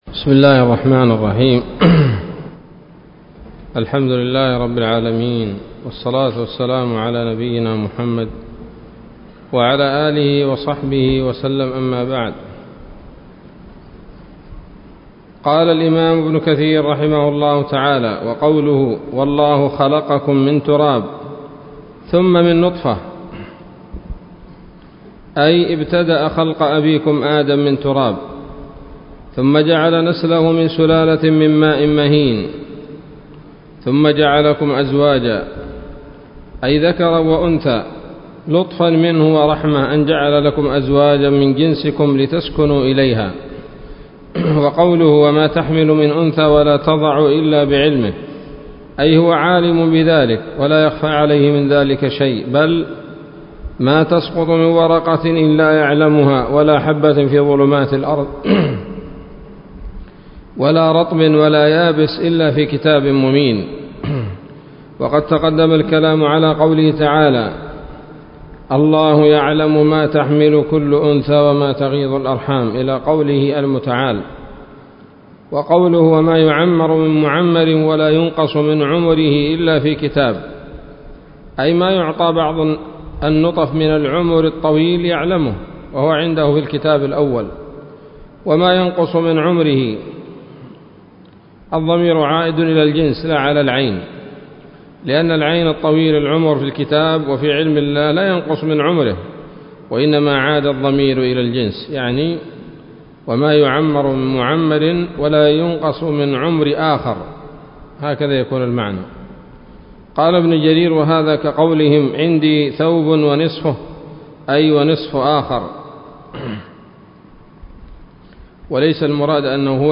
الدرس الرابع من سورة فاطر من تفسير ابن كثير رحمه الله تعالى